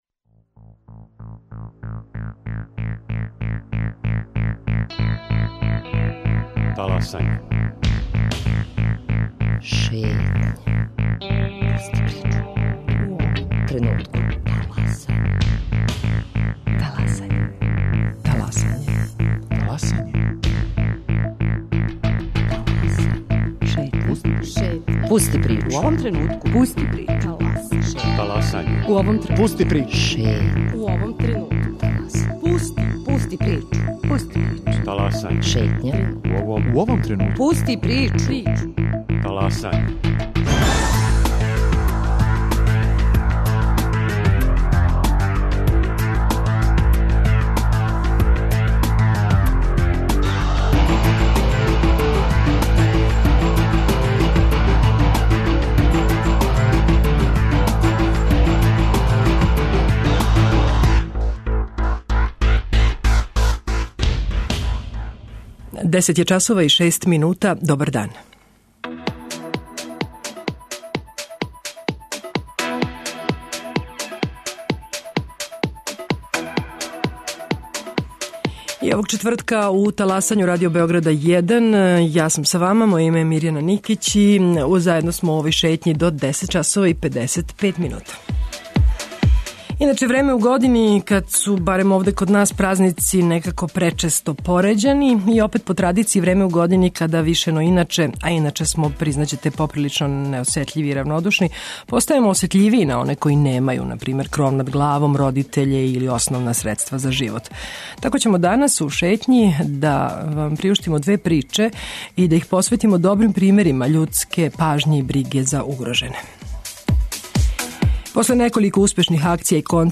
Чућете и разговор са београдским таксистом о предностима и манама посла без радног времена.